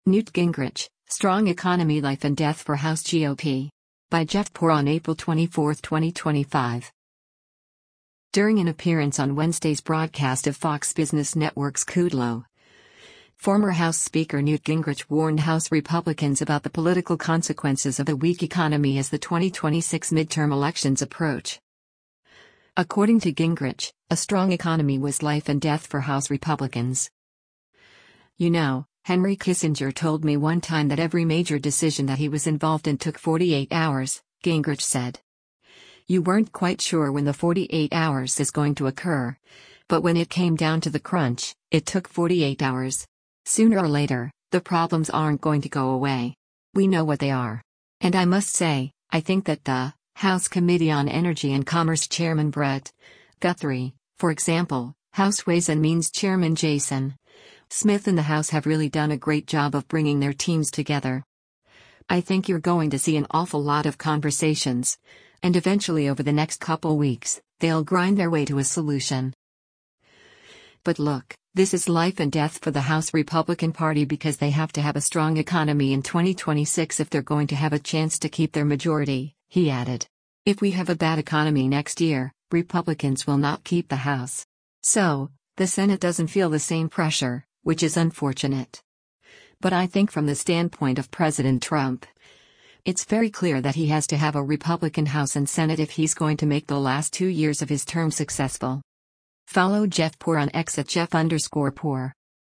During an appearance on Wednesday’s broadcast of Fox Business Network’s “Kudlow,” former House Speaker Newt Gingrich warned House Republicans about the political consequences of a weak economy as the 2026 midterm elections approach.